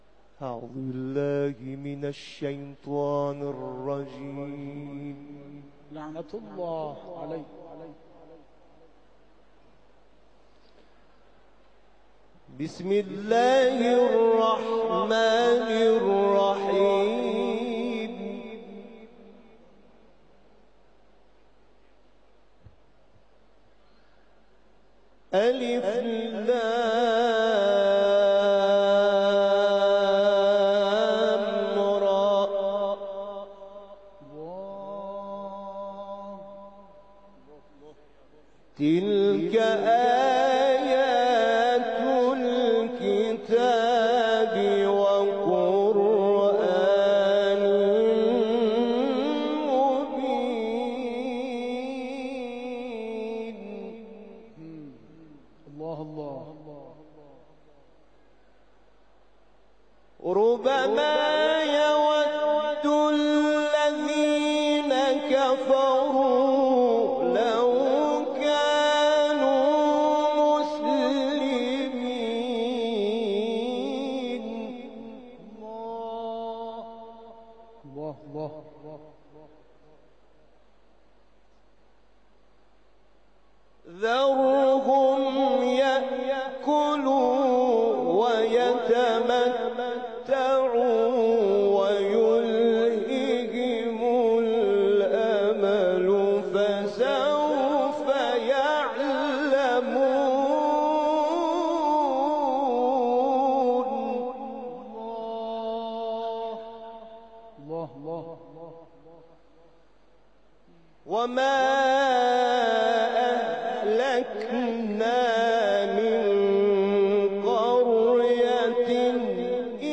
کرسی تلاوت رضوی